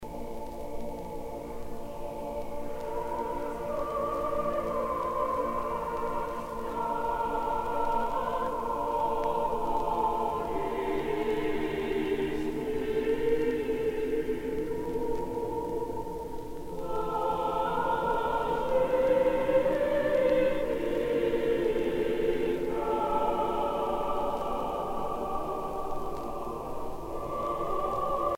dévotion, religion
Pièce musicale éditée